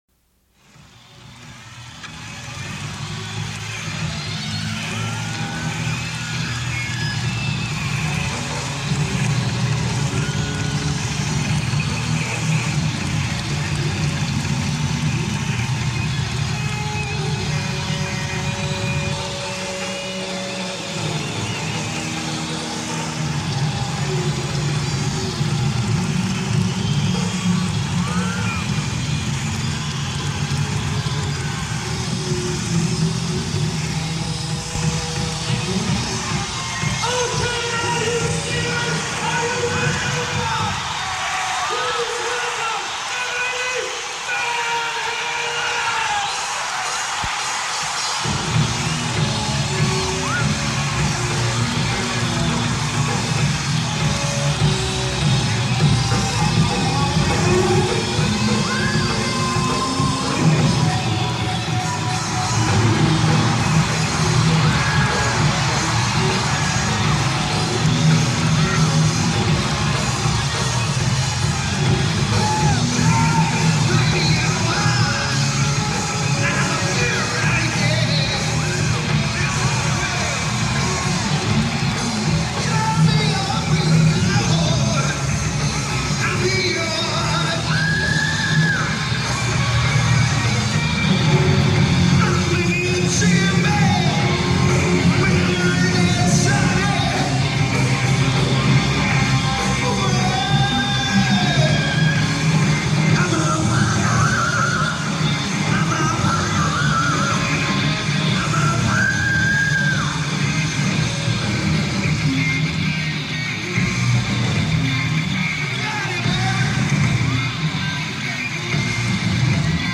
Quality is solid B+ on this one.